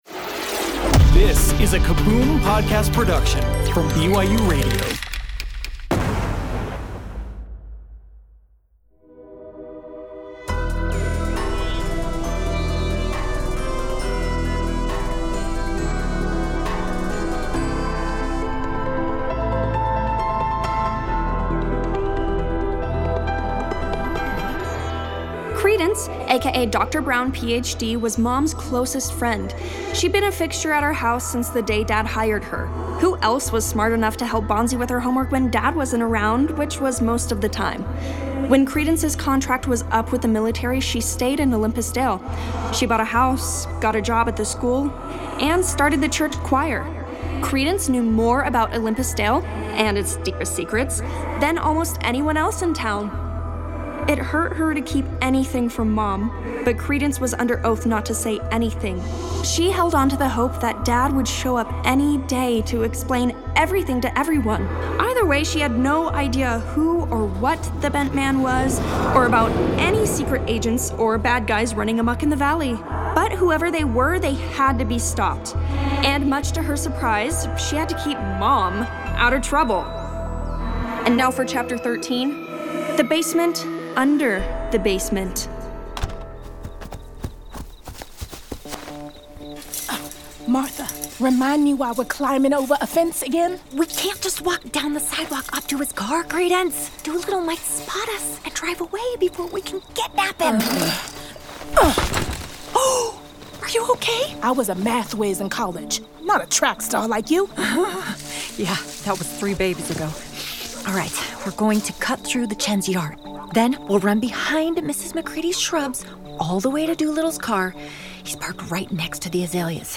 Bedtime Stories Audio Drama BYUradio Sci-Fi / Fantasy Stories Content provided by BYUradio.